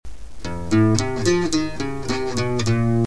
Requinto2
Lam#,Fa7, Lam#,